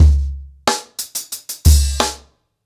CornerBoy-90BPM.31.wav